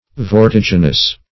Search Result for " vortiginous" : The Collaborative International Dictionary of English v.0.48: Vortiginous \Vor*tig"i*nous\, a. [Cf. Vertiginous .]